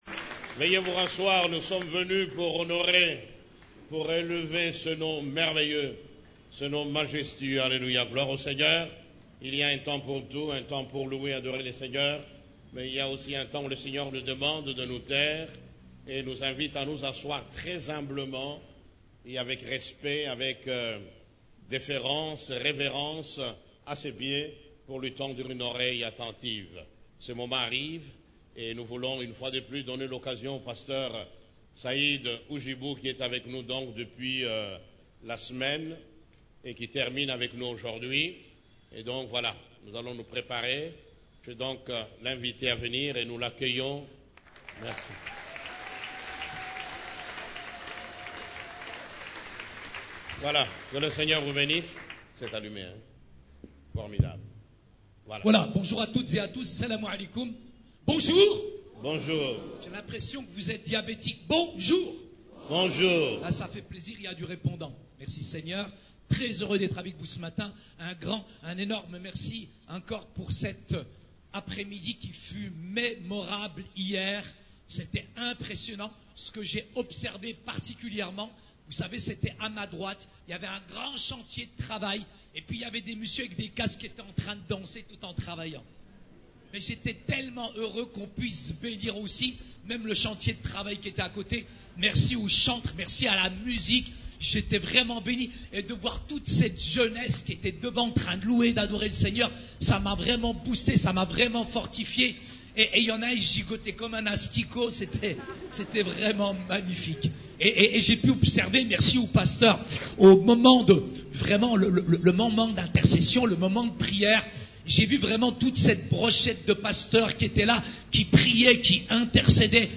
CEF la Borne, Culte du Dimanche, Jusqu'à quand ?